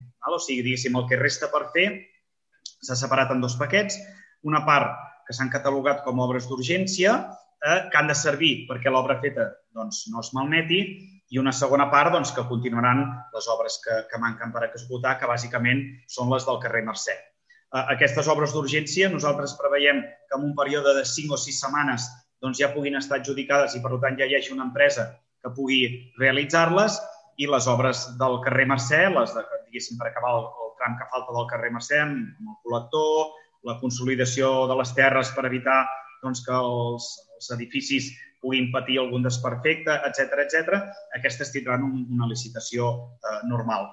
Tal com va explicar l’alcalde Lluís Puig a Ràdio Palamós, les obres pendents estan dividides en dos paquets.